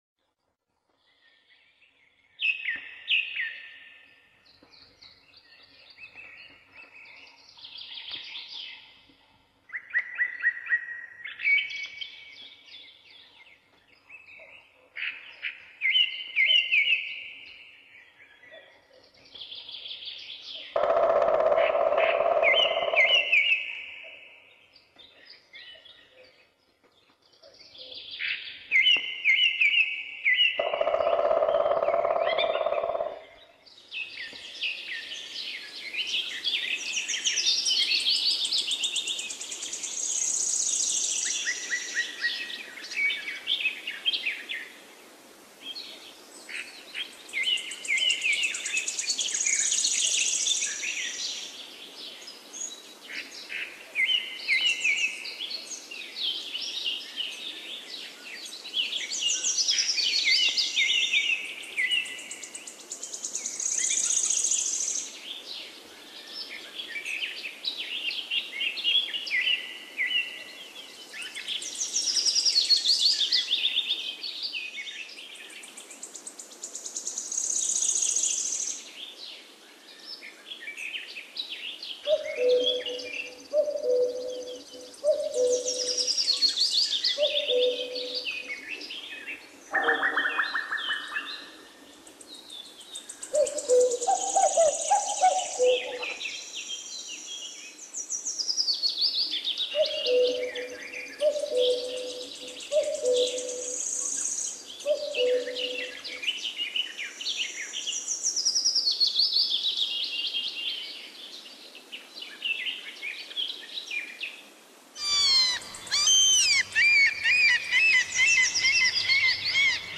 Soothing Nature Sounds for Sleeping - Health Advisor
Nature Sounds For Sleeping